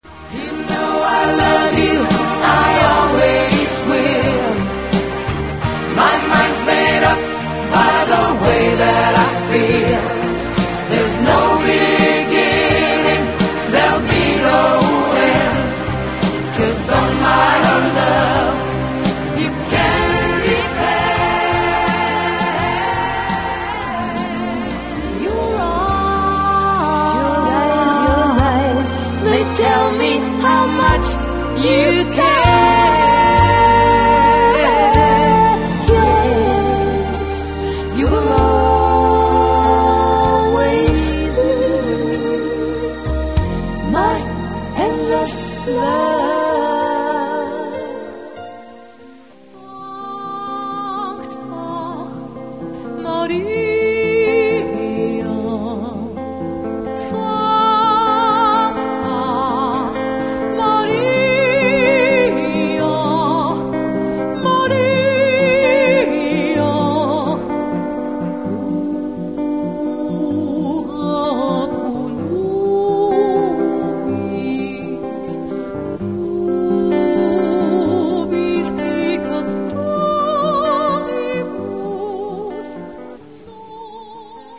Trauungsprogramm